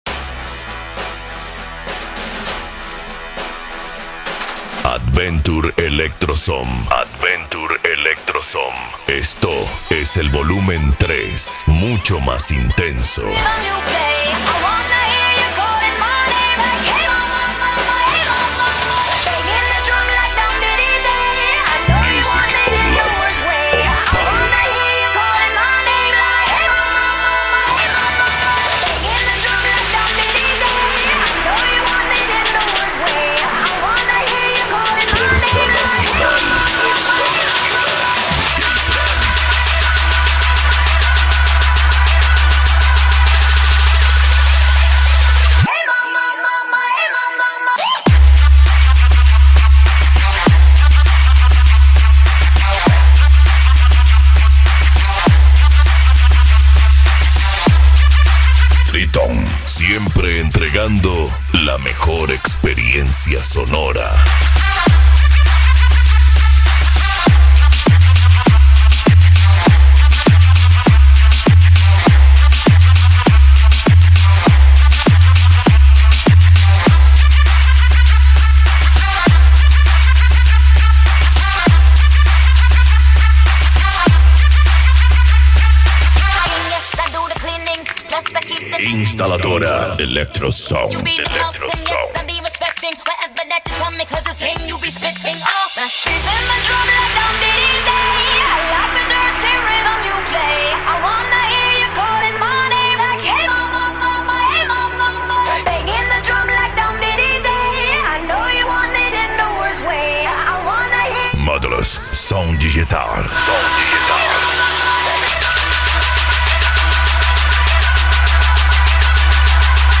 Eletronica
Psy Trance
Remix